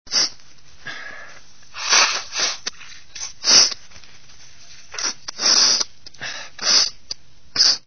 Descarga de Sonidos mp3 Gratis: aspirar 1.
sniffles.mp3